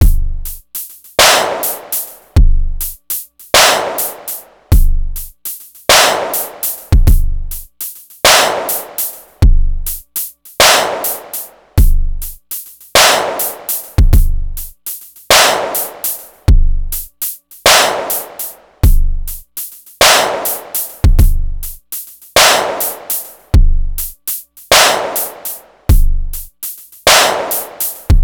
DRM1 Via MPC3K 12 Bar Loop.wav PlayStop
DRM1-Via-MPC3K-12-Bar-Loop.wav